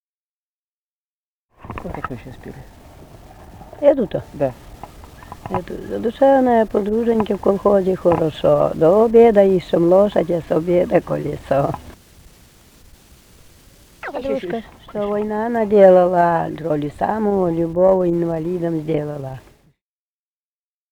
Живые голоса прошлого 036. «Задушевная подруженька» (частушки).